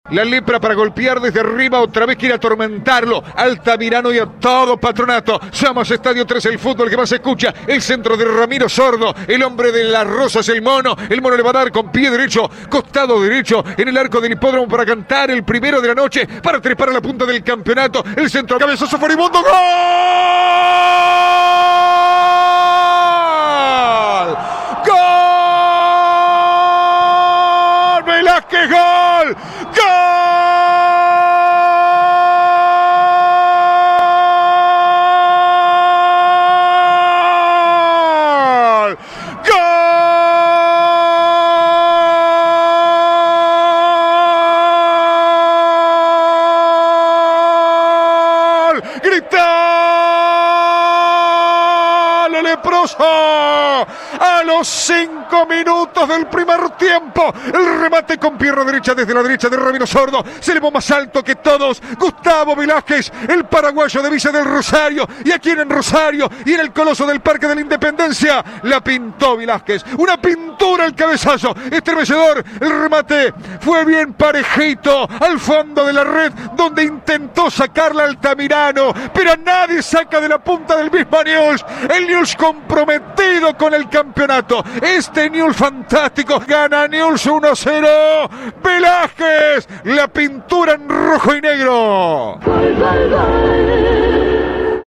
Audio. 1º gol de Newells (Velázquez) - relato